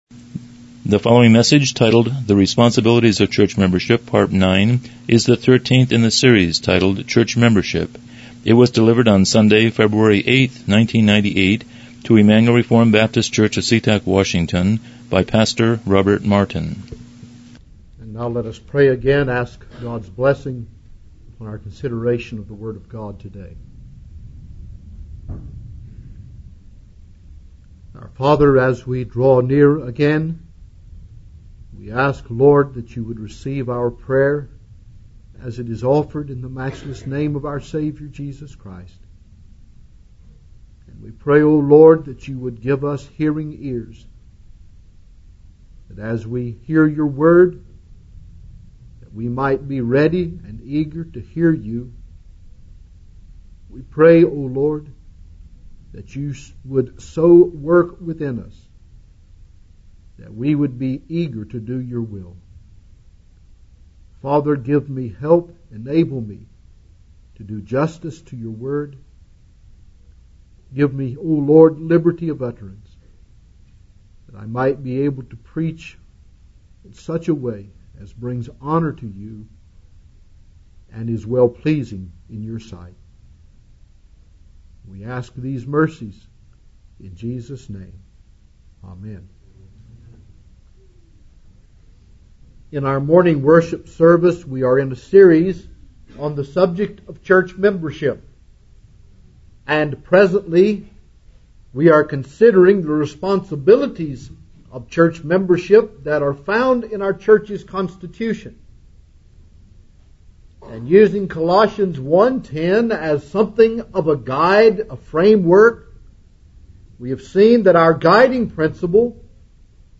Church Membership Service Type: Morning Worship « 12 Responsibilities of